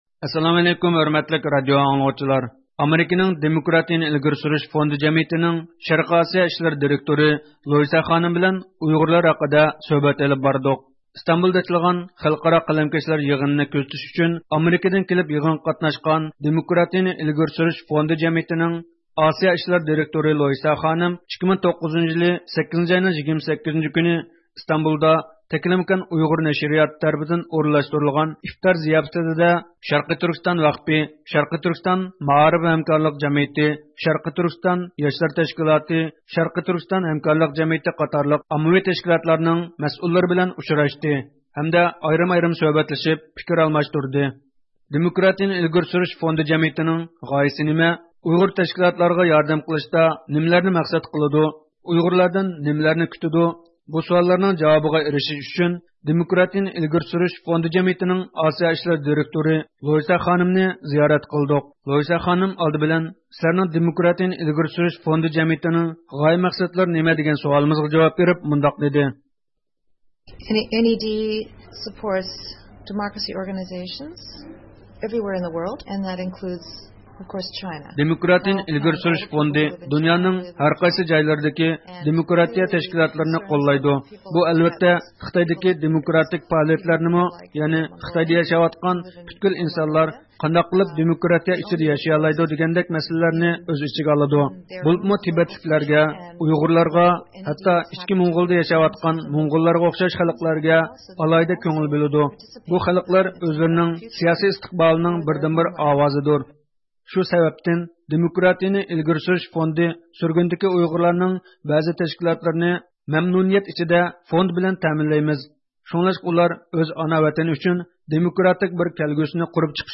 سۆھبەت